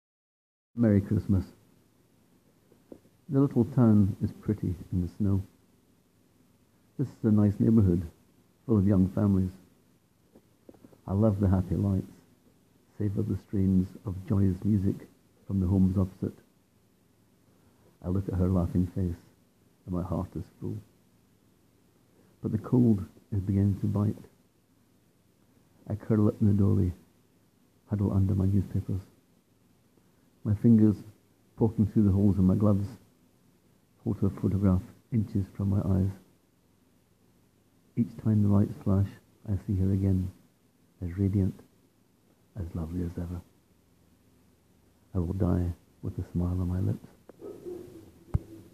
Click here to hear me read this 1-minute story (with Eros providing background music at the end!):